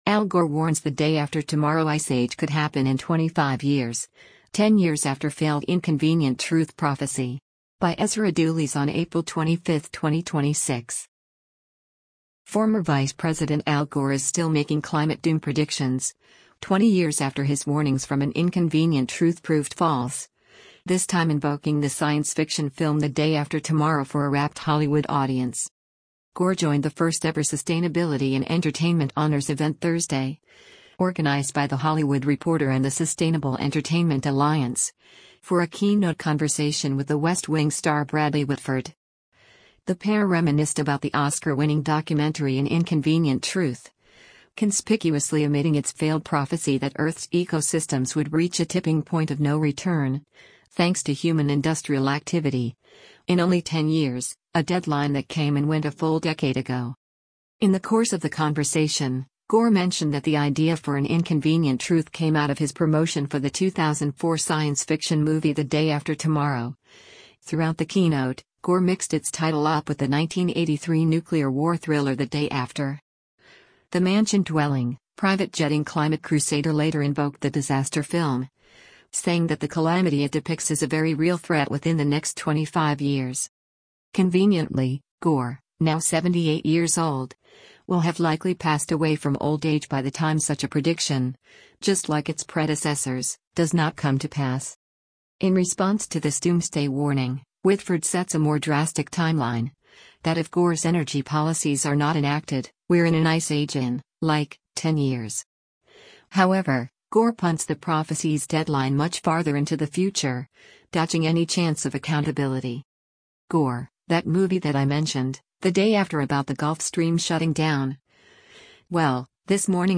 Al Gore speaks onstage as The Hollywood Reporter & Sustainable Entertainment Alliance host
Gore joined the first-ever Sustainability in Entertainment Honors event Thursday, organized by The Hollywood Reporter and the Sustainable Entertainment Alliance, for a keynote conversation with The West Wing star Bradley Whitford.